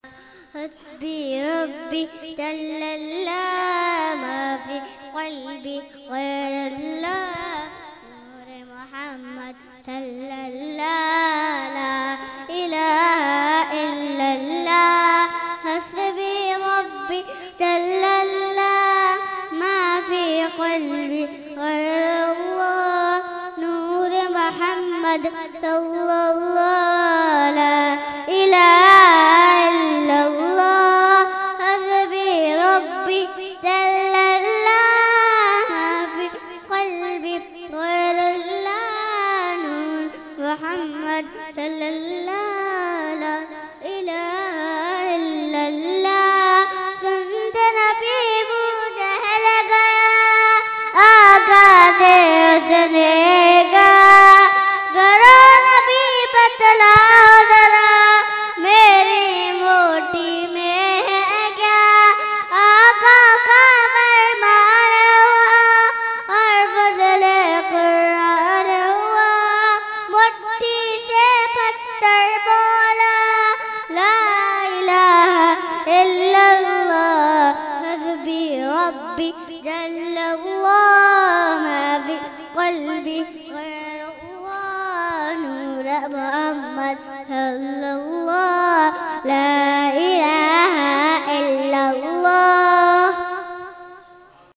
Mehfil e 11veen Shareef held 11 December 2020 at Dargah Alia Ashrafia Ashrafabad Firdous Colony Gulbahar Karachi.
Category : Naat | Language : UrduEvent : 11veen Shareef 2020